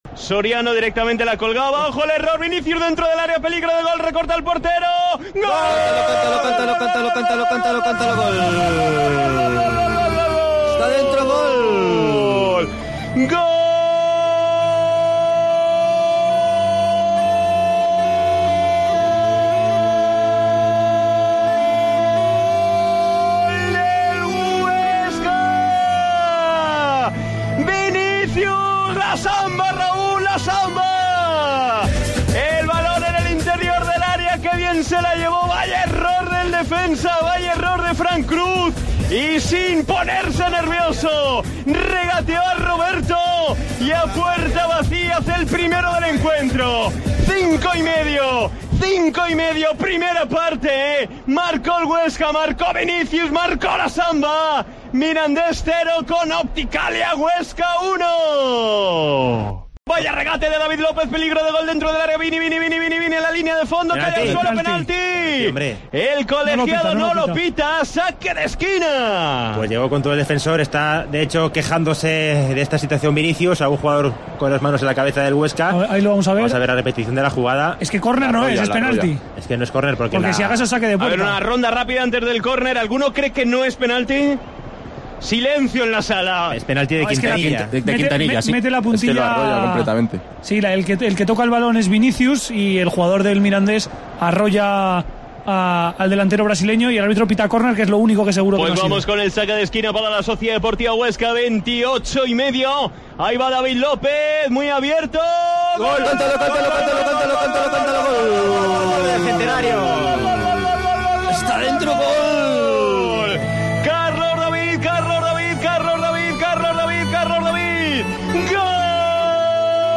Así cantamos los goles en la retransmisión local